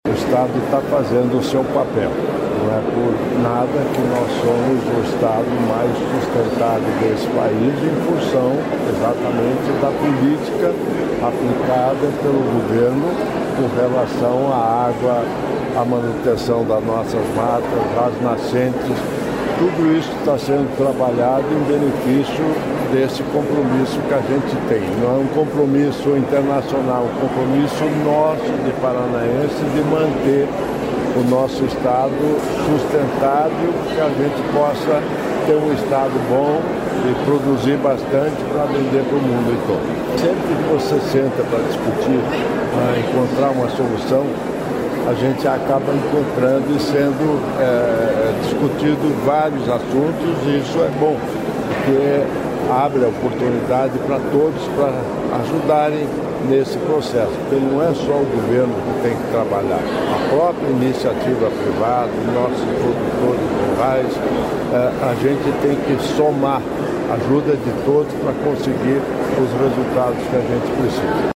Sonora do vice-governador Darci Piana sobre os primeiros resultados do Programa Paranaense de Mudanças Climáticas